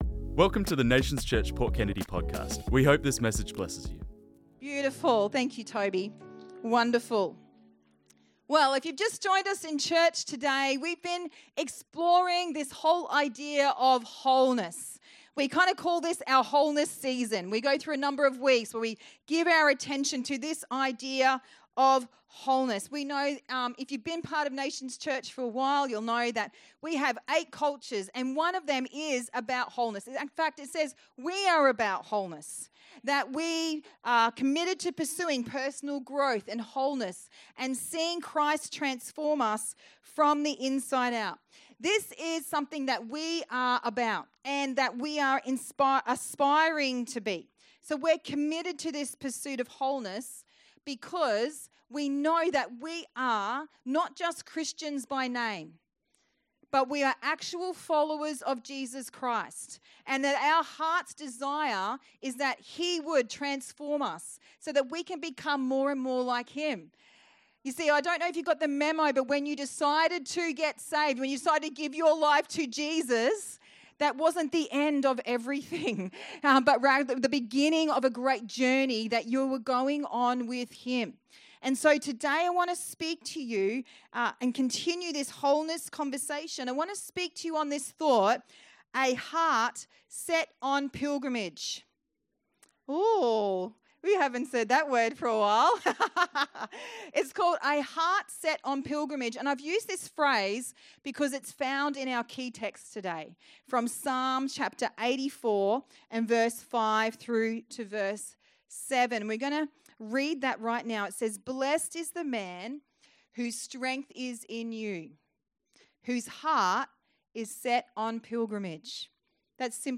This message was preached on Sunday the 24th August 2025